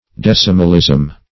Search Result for " decimalism" : The Collaborative International Dictionary of English v.0.48: decimalism \dec"i*mal*ism\, n. The system of a decimal currency, decimal weights, measures, etc. [1913 Webster]
decimalism.mp3